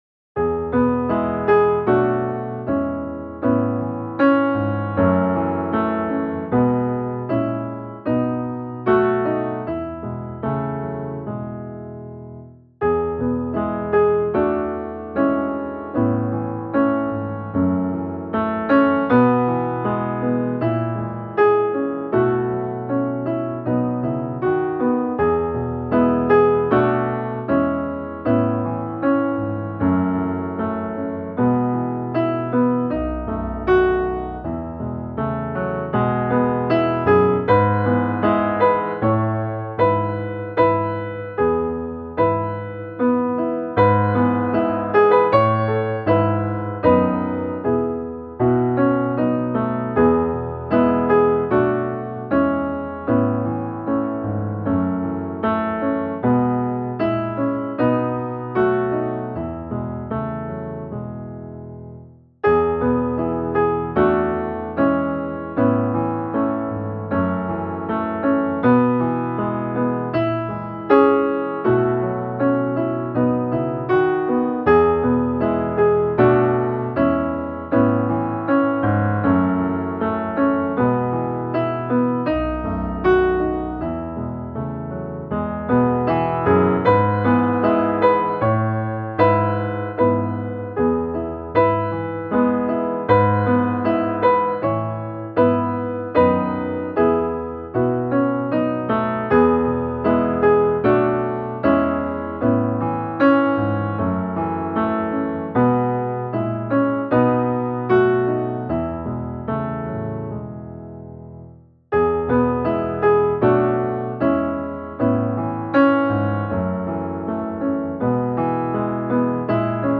Närmare, Gud, till dig - musikbakgrund
Musikbakgrund Psalm